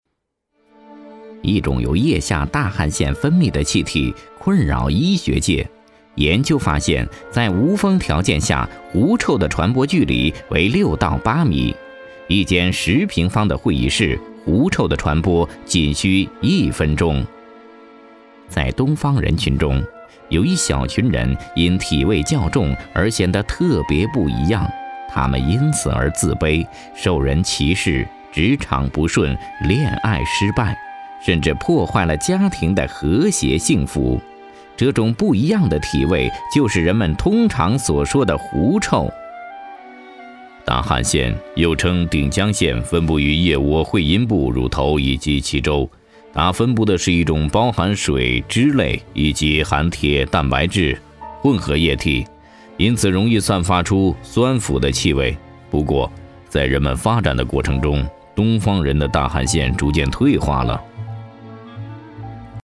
2 男国102_纪录片_自然科教_医药探秘大汗腺任志宏 男国102
男国102_纪录片_自然科教_医药探秘大汗腺任志宏.mp3